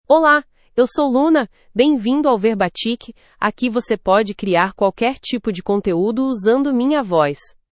Luna — Female Portuguese (Brazil) AI Voice | TTS, Voice Cloning & Video | Verbatik AI
Luna is a female AI voice for Portuguese (Brazil).
Voice sample
Female
Luna delivers clear pronunciation with authentic Brazil Portuguese intonation, making your content sound professionally produced.